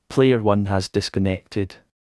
netplay_p1_disconnected.wav